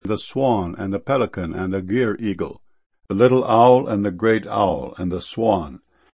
swan.mp3